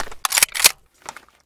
vityaz_unjam.ogg